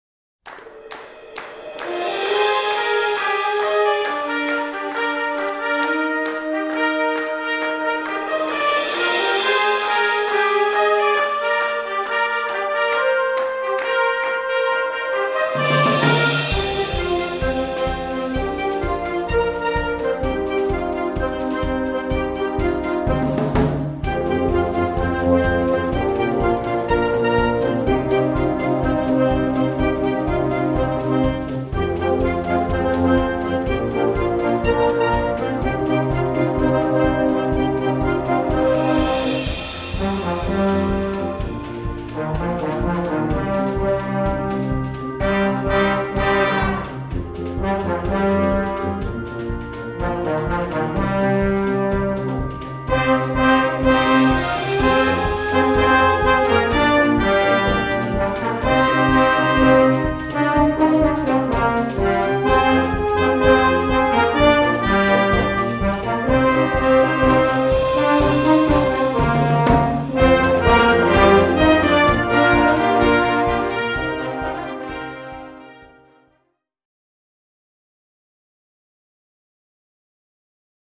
3:29 Minuten Besetzung: Blasorchester Zu hören auf